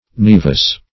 nevus - definition of nevus - synonyms, pronunciation, spelling from Free Dictionary
Naevus \Nae"vus\ (n[=e]"v[u^]s), n.; pl. Naevi (-v[imac]).